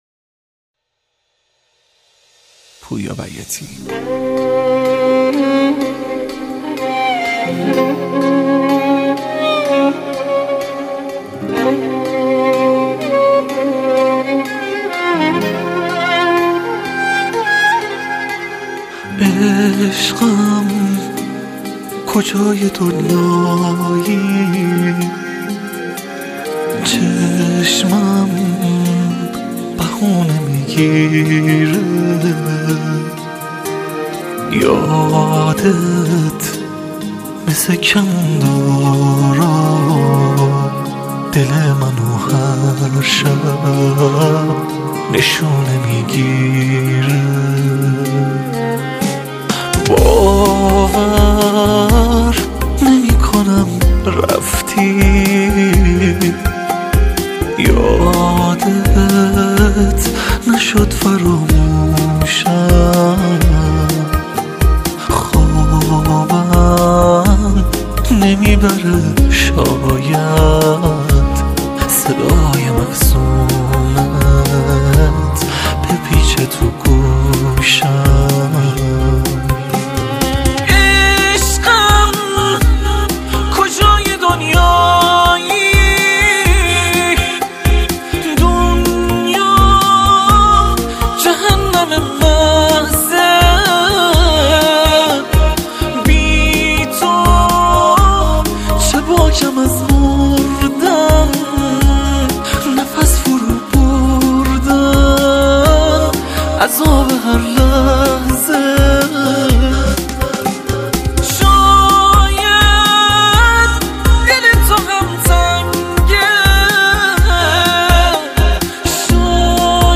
وقتی غمگین میخونی صدا باحال تر میشه